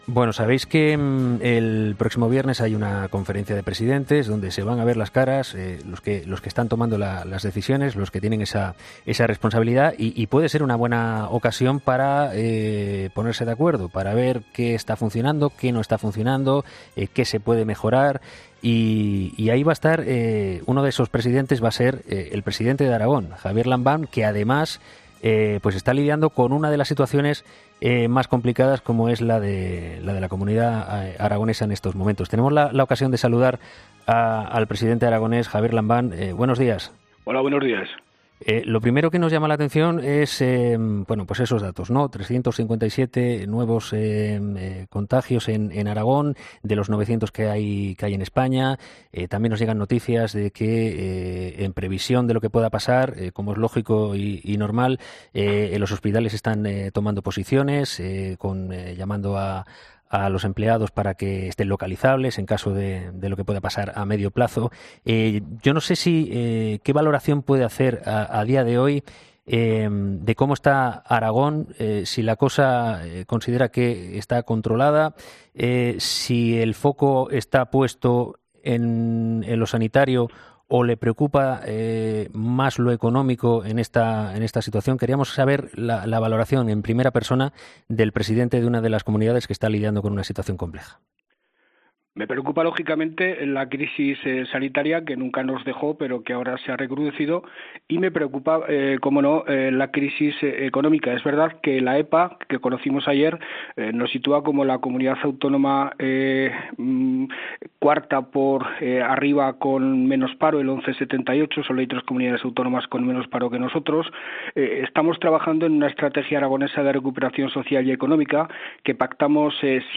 Javier Lambán, presidente de Aragón, ha sido entrevistado este miércoles en 'Herrera en COPE' después de que la comunidad haya registrado este martes la cifra más alta de nuevos contagios a nivel nacional –367-.